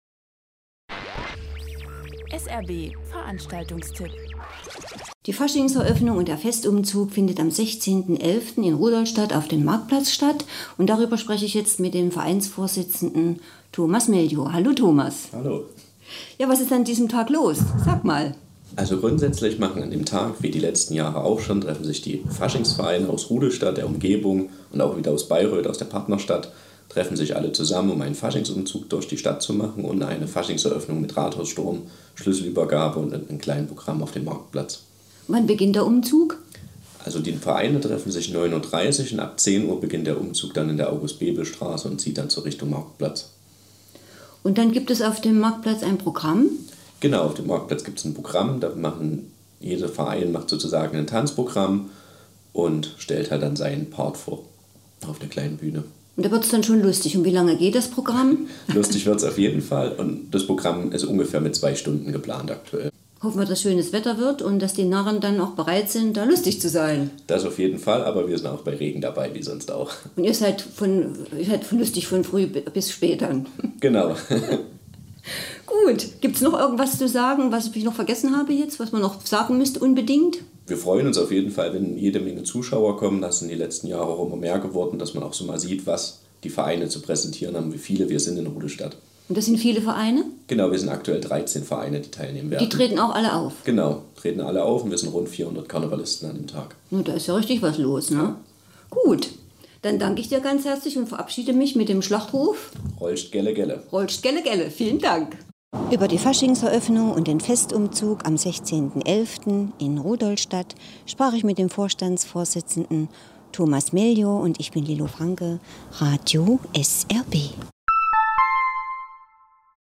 Magazinbeitrag/ Interview im Radio SRB.